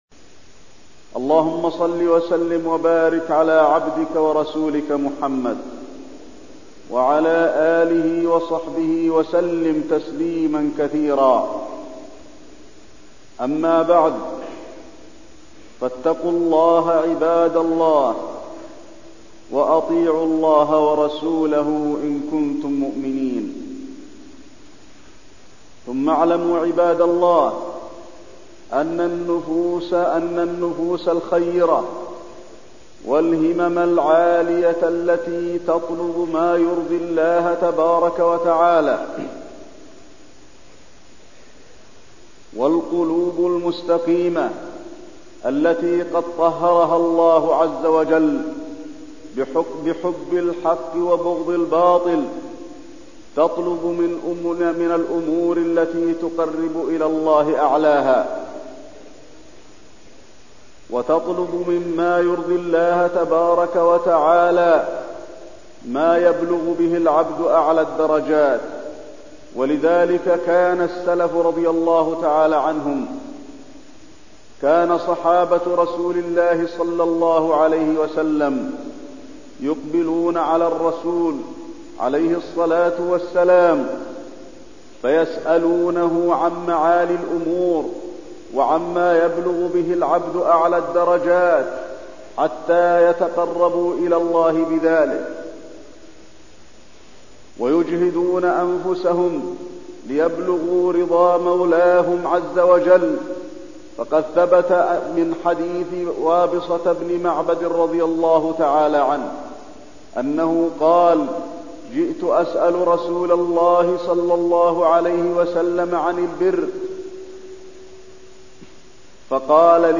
تاريخ النشر ١ رجب ١٤٠٥ هـ المكان: المسجد النبوي الشيخ: فضيلة الشيخ د. علي بن عبدالرحمن الحذيفي فضيلة الشيخ د. علي بن عبدالرحمن الحذيفي البر